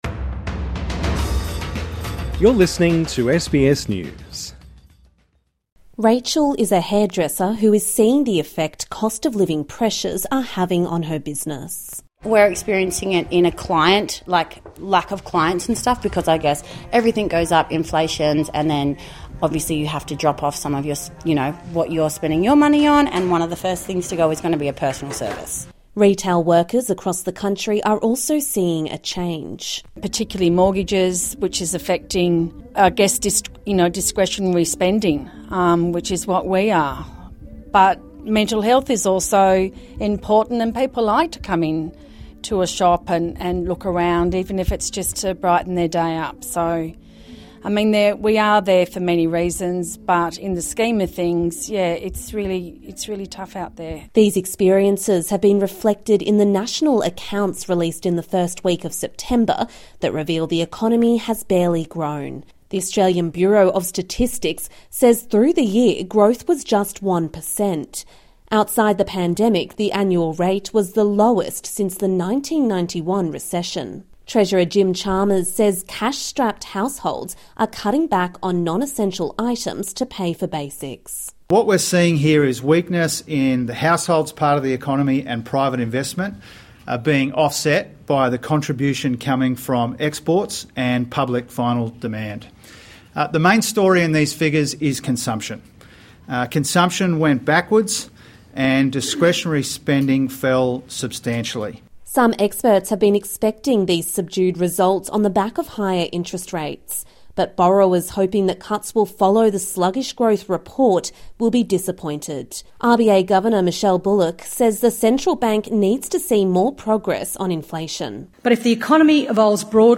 Reserve Bank of Australia Governor Michele Bullock delivers a speech on the costs of high inflation.